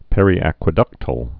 (pĕrē-ăkwĭ-dŭktəl)